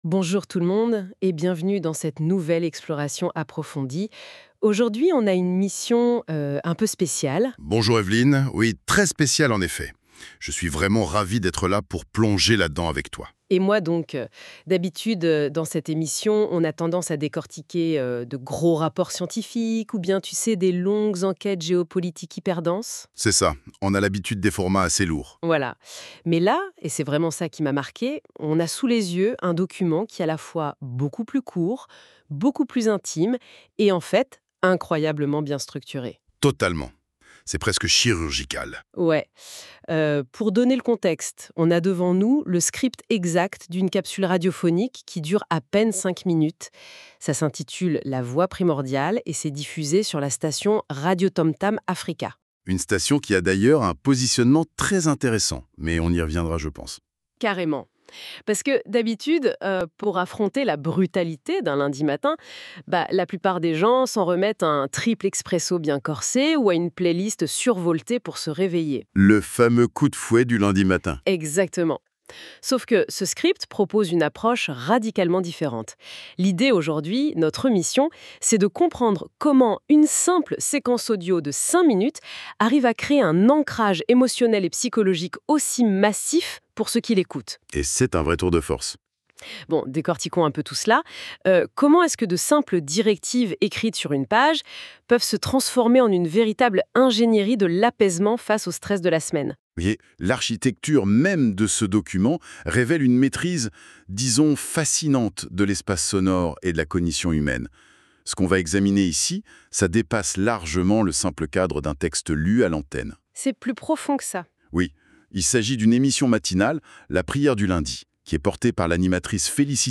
PODCAST – PRIÈRE DU LUNDI